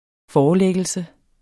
Udtale [ ˈfɒːɒˌlεgəlsə ]